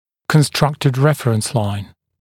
[kən’strʌktɪd ‘refrəns laɪn][кэн’страктид ‘рэфрэнс лайн]построенная относительная линия (напр. франкфуртская горизонталь, построенная от линии sella-nasion)